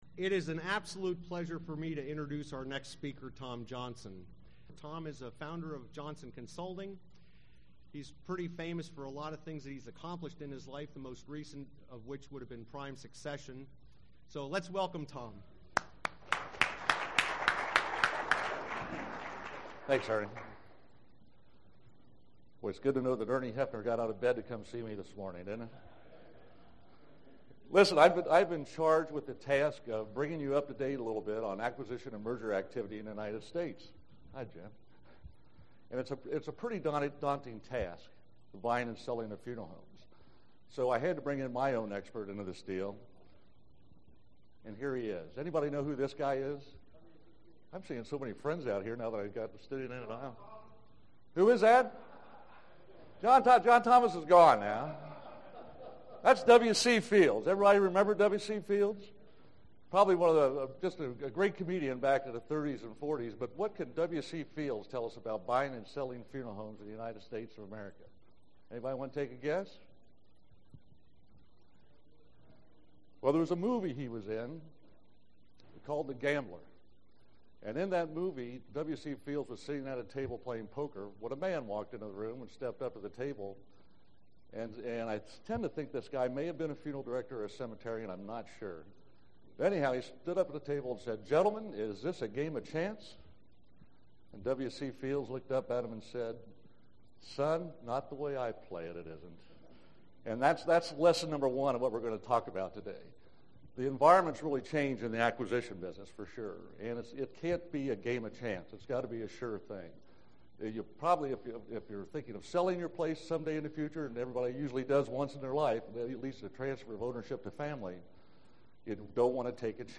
ICFA 2006 Convention